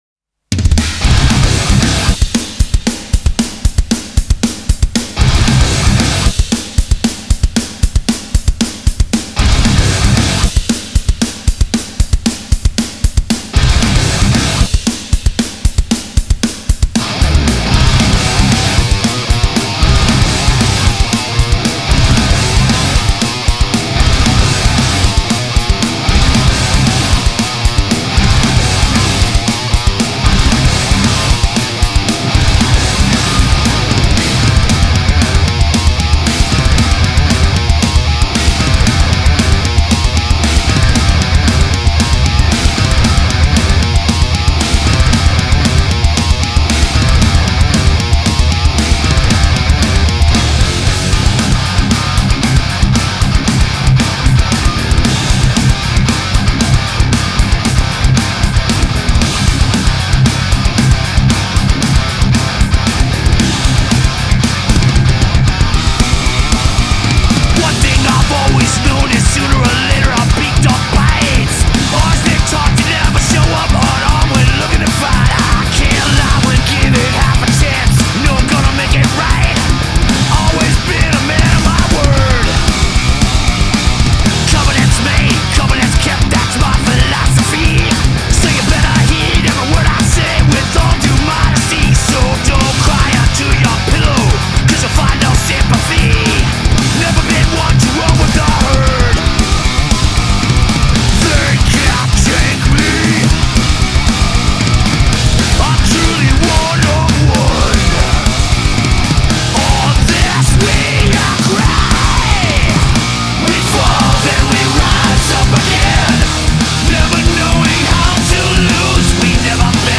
Трек размещён в разделе Зарубежная музыка / Метал.